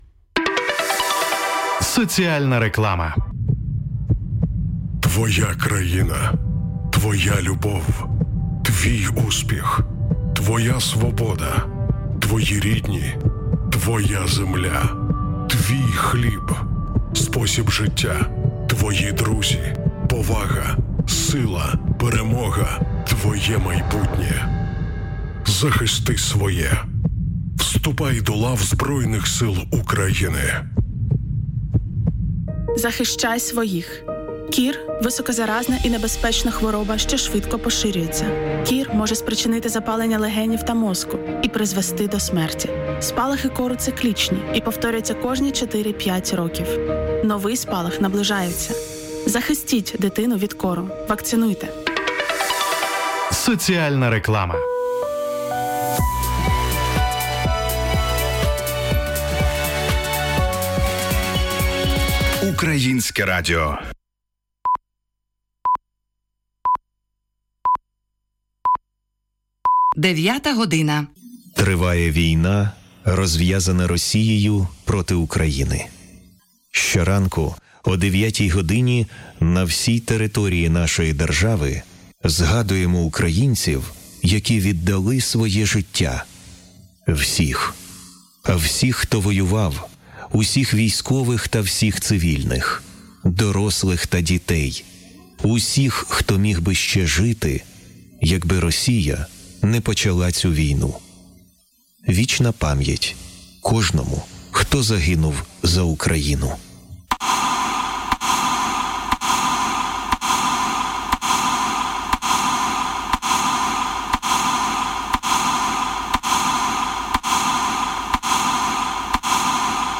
Publicitat, identificació de l'emissora (Ukrayinske Radio), senyals horaris, missatge, salves, himne d'Ucraïna, identificació, notícies.
Informatiu
DAB